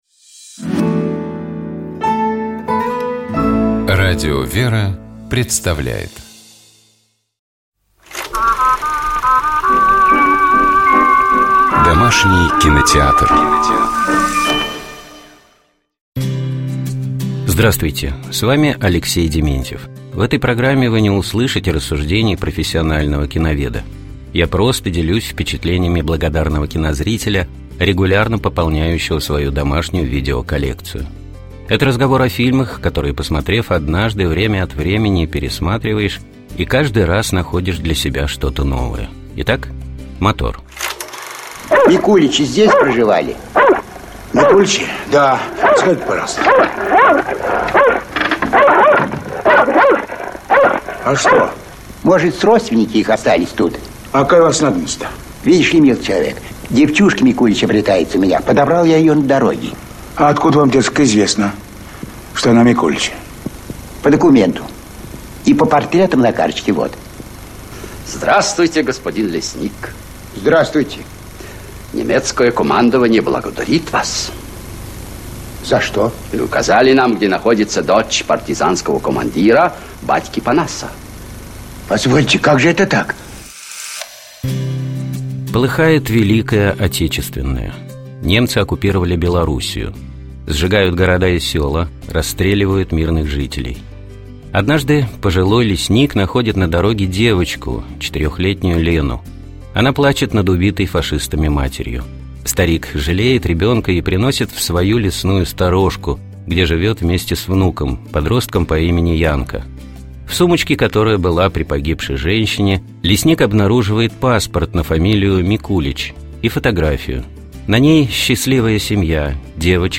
Дета разговаривают